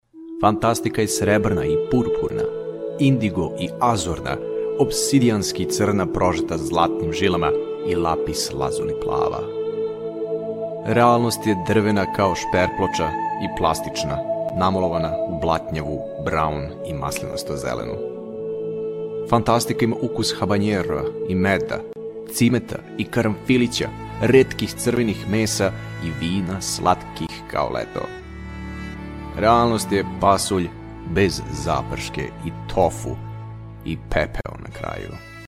塞尔维亚语翻译团队成员主要由中国籍和塞尔维亚籍的中塞母语译员组成，可以提供证件类翻译（例如，驾照翻译、出生证翻译、房产证翻译，学位证翻译，毕业证翻译、无犯罪记录翻译、营业执照翻译等）、公证书翻译、技术文件翻译、工程文件翻译、合同翻译、审计报告翻译等；塞尔维亚语配音团队由塞籍塞尔维亚语母语配音员组成，可以提供专题配音、广告配音、教材配音、电子读物配音、产品资料配音、宣传片配音、彩铃配音等。
塞尔维亚语样音试听下载